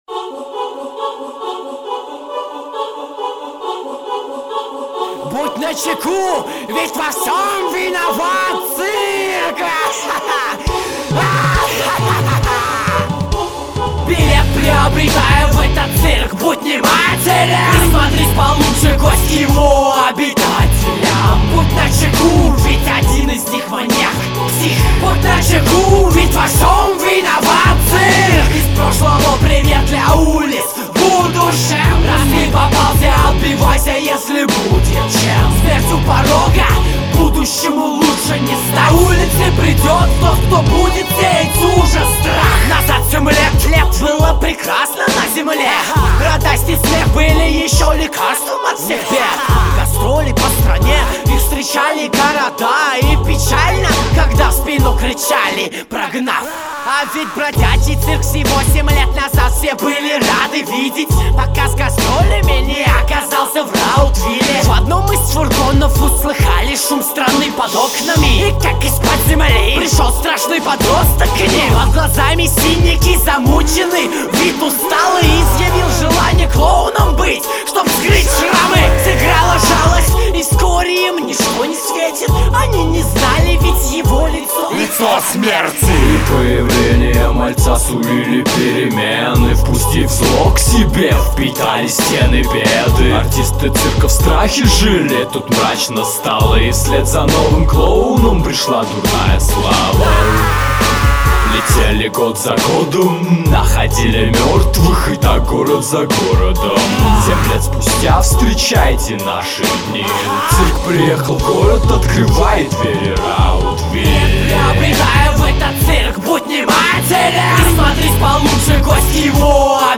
Плотный такой хасол
Опасный репчег
Голос охуенный